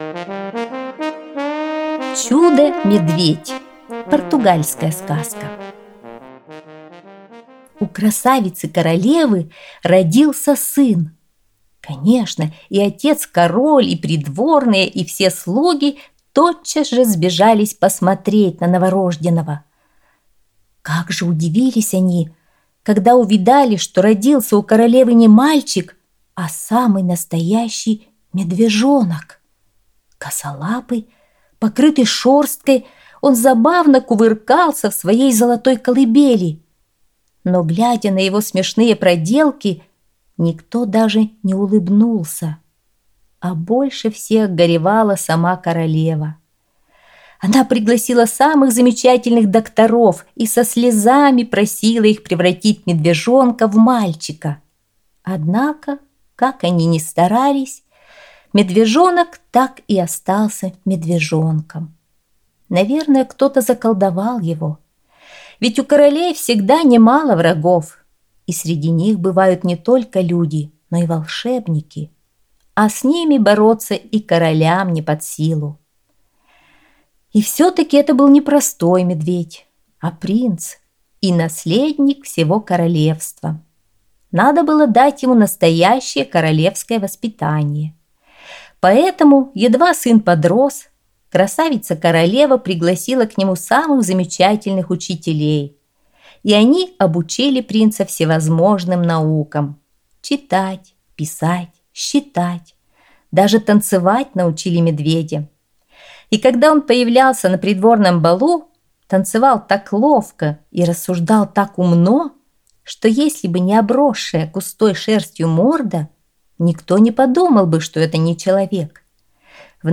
Чудо-медведь - португальская аудиосказка - слушать онлайн